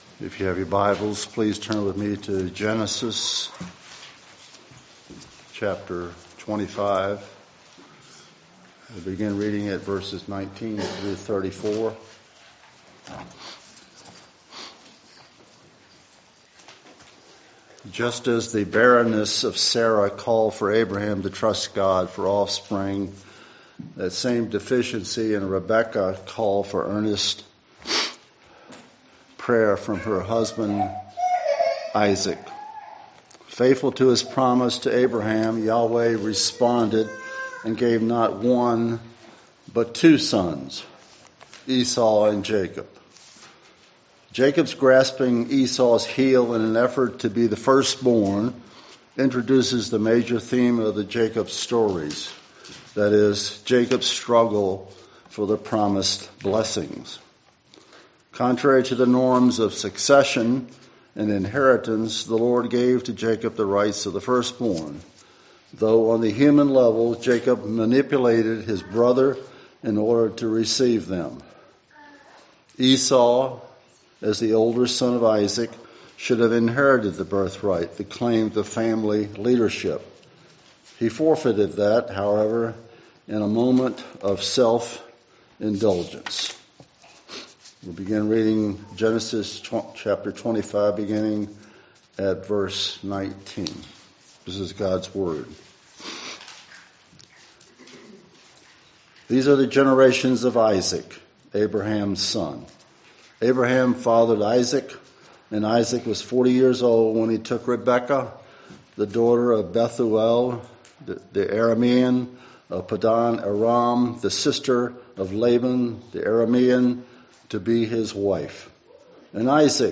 … continue reading 11 epizódok # Religion # West # Suffolk # Baptist # Church # Reformed # West Suffolk Baptist Church # Christianity # Sermons # WSBC